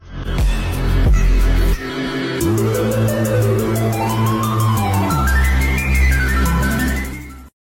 sorry for the bad quality